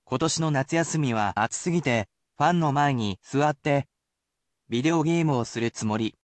And, I will speak aloud the sentence example.